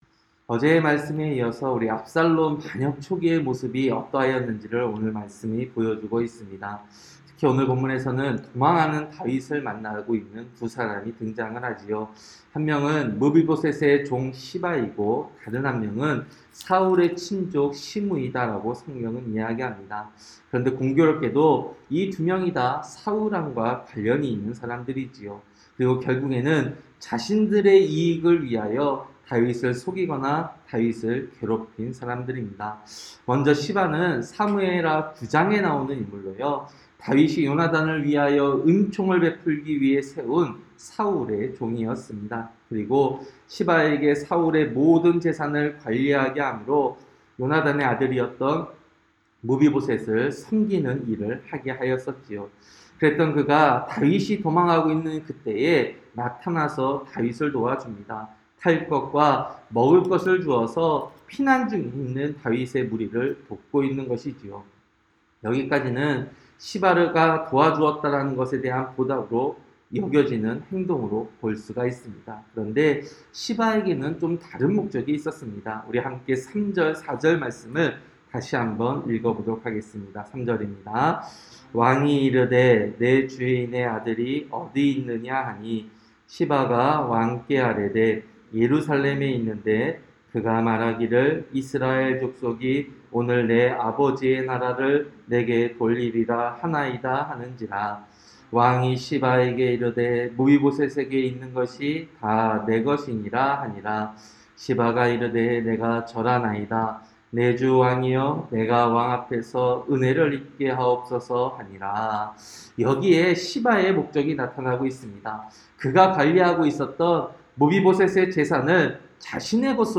새벽설교-사무엘하 16장